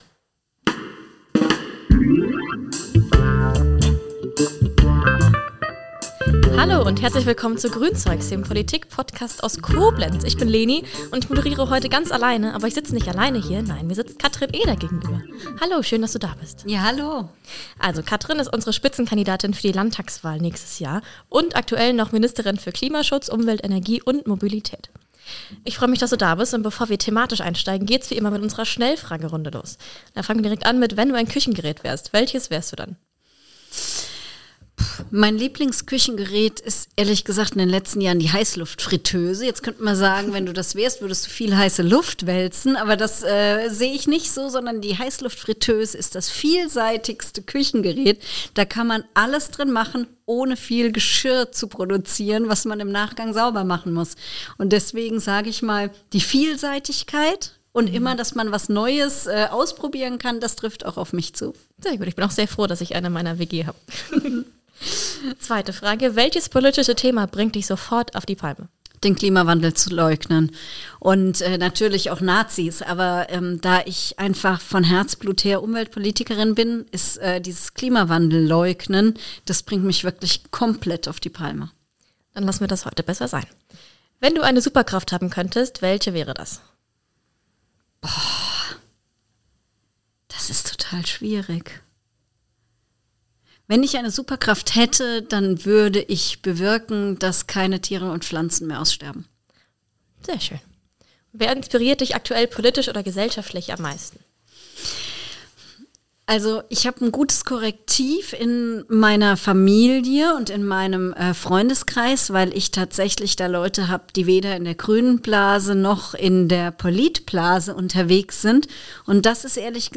Ist unsere Gesellschaft im Rückwärtsgang? Im Gespräch mit Katrin Eder ~ Grünzeugs Podcast